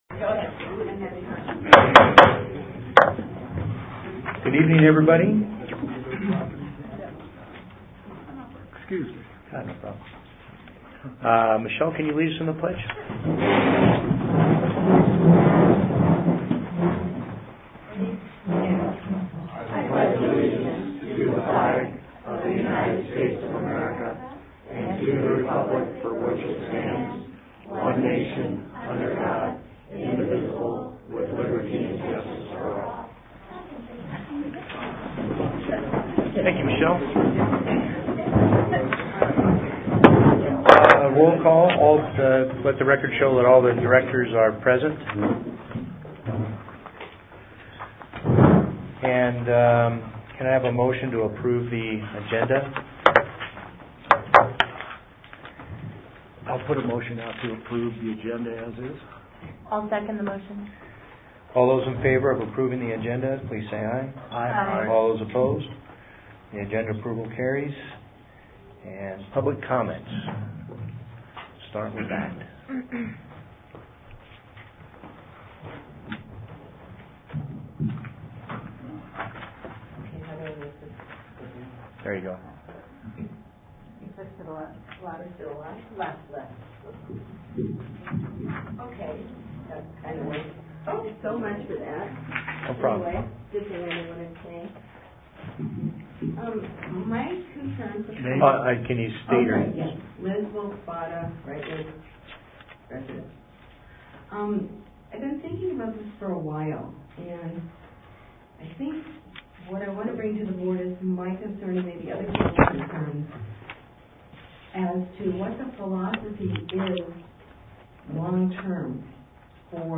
Sept 4 2018 Regular Board Meeting